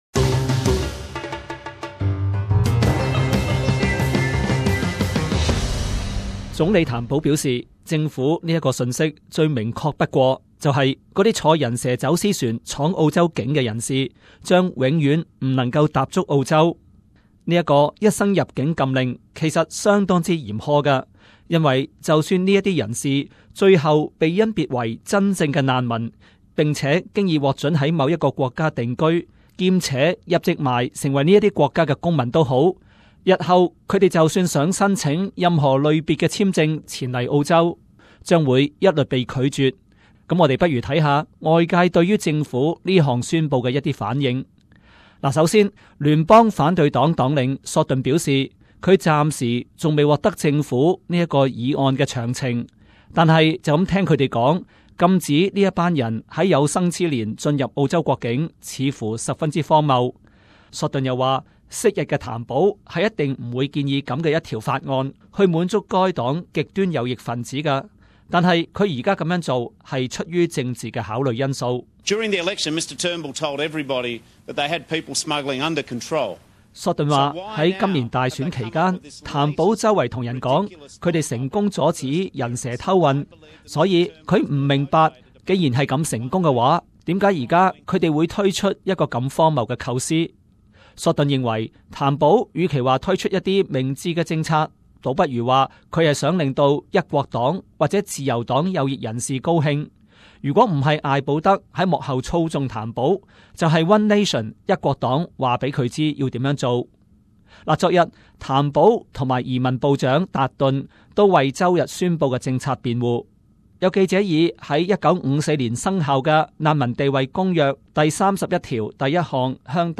【時事報導】 工黨將仔細研究禁船民終身踏足澳洲議案